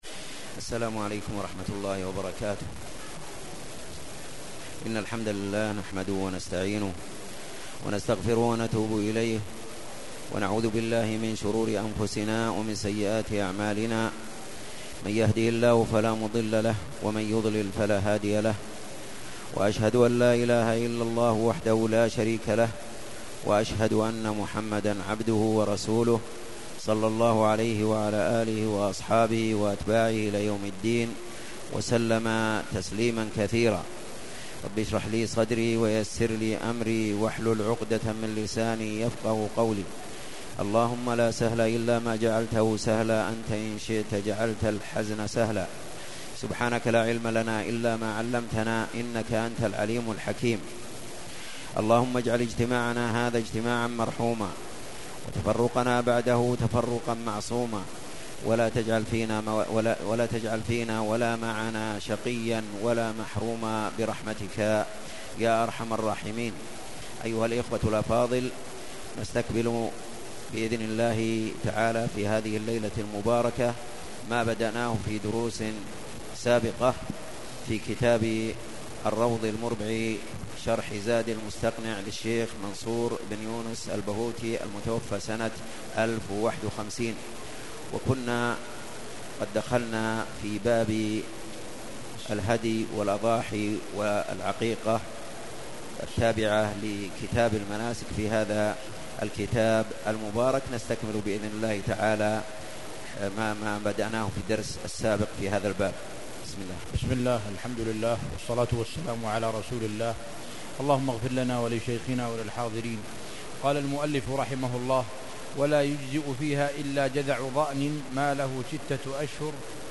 تاريخ النشر ٩ ربيع الأول ١٤٣٩ هـ المكان: المسجد الحرام الشيخ